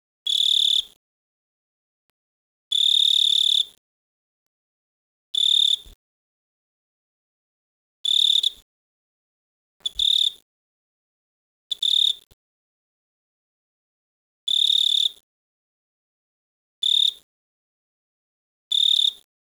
I ran to grab my recorder and was able to capture a snippet of the call.
The call was that of the Jumping Bush Cricket (Orocharis saltator), a species I first encountered a couple of years ago in Cambridge, MA.
Sonogram of the Jumping Bush Cricket (Orocharis saltator); click here to listen to the recording.
orocharis_saltator.wav